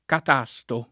catasto [
kat#Sto] s. m. — dell’uso più antico (a Venezia dal Medioevo al ’600) l’originario grecismo catastico [kat#Stiko] (pl. -ci), vicino per l’etimo a distico, acrostico, ecc.; pure dell’uso antico (almeno a Perugia nel ’300) la var. catastro [kat#Stro], con un’-r- non etimologica (come in balestra, ginestra, ecc.) che poi si ritrova nel fr. cadastre, nato come italianismo nel ’500; sul modello di questo, la più moderna var. cadastro [kad#Stro], diffusa soprattutto nell’800 (più che altro in Piem. e Lomb.), ma poi scomparsa — solo catasto nell’uso tosc. dal ’300 in poi; e solo da catasto, modernam., l’agg. catastale [kataSt#le] — sim. il cogn. Catasti